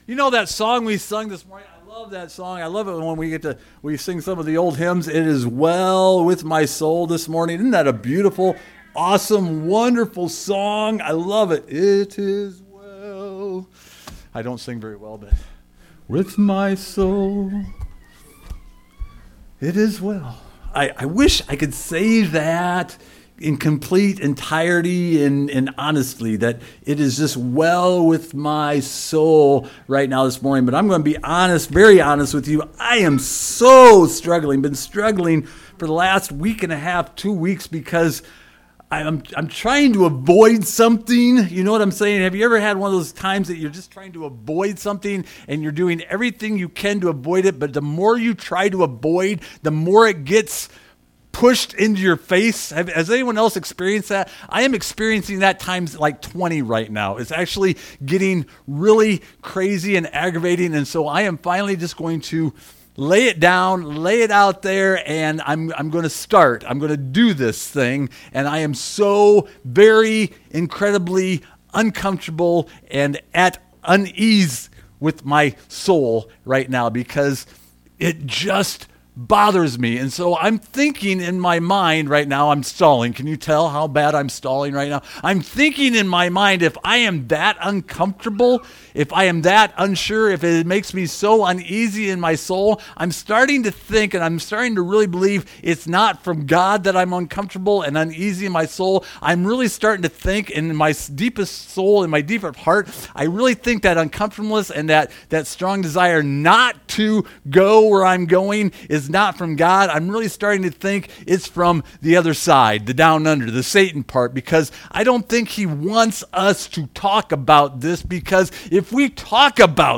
Opening sermon exploring the connection between our spiritual lives and our financial lives as presented in the gospel. Sermon addresses the human search for power/influence, pleasure, and treasure.